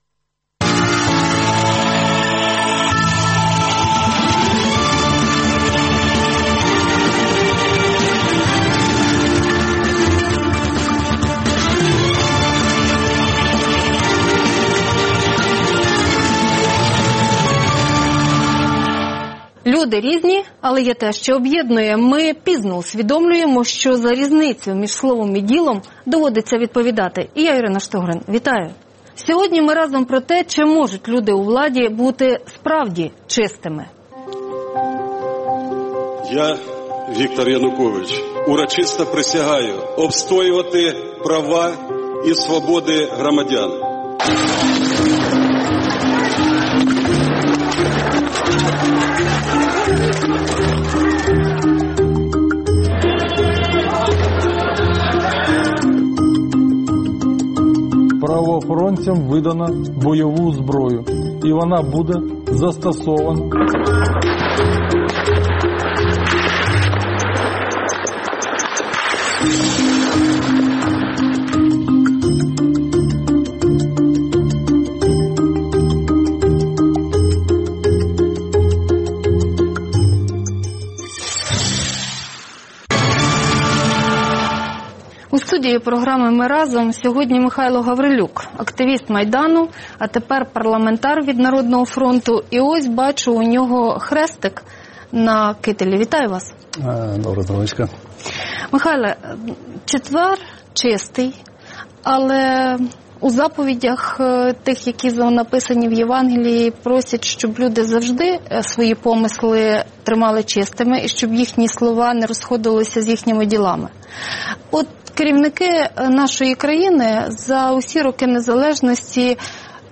Гість: Михайло Гаврилюк, народний депутат, майданівець